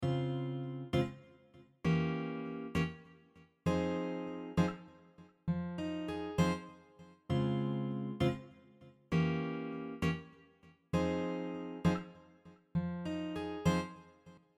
The E dim7 is acting as a 5 chord (C7 b9) of the F minor 7 chord.
Diminished-chord-progressions-on-guitar-3.mp3